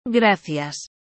Como se pronuncia gracias?